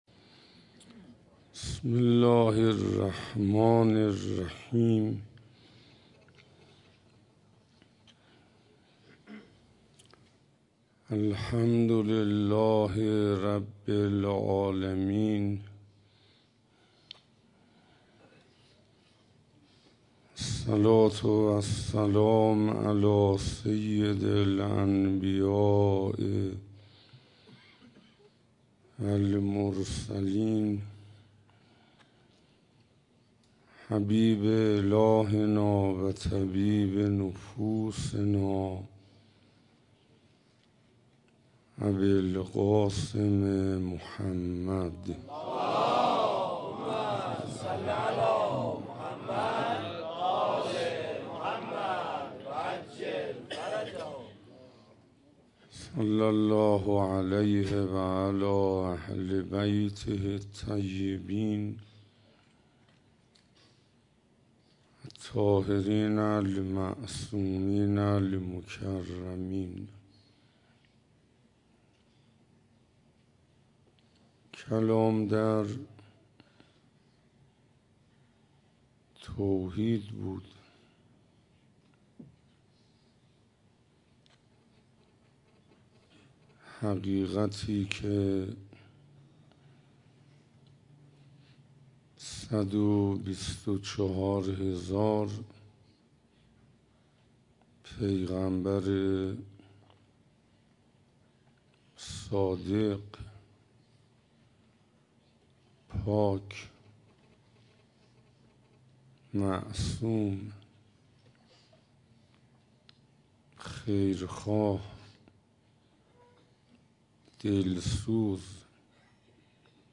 روز هشتم محرم 97 - حسینیه علوی تهرانی - زندگی توحیدی